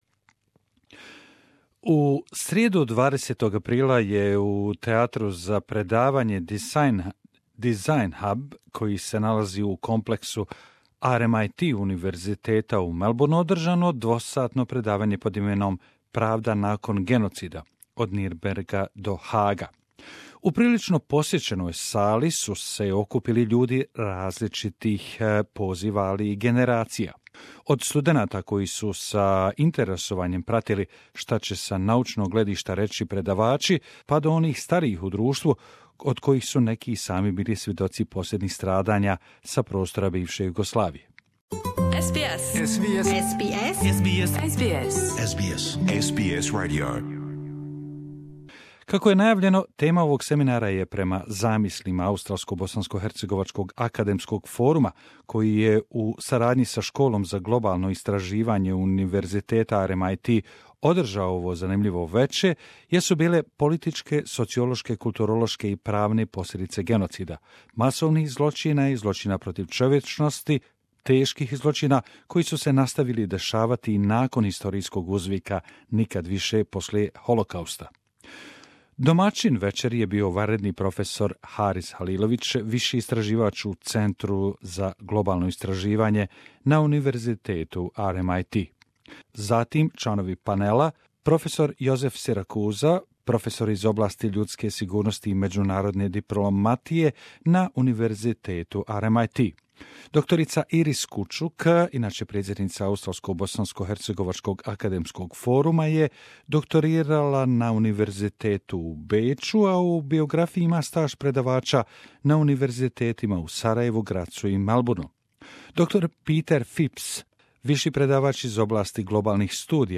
Free public seminar in lecture theatre in the Design Hub Melbourne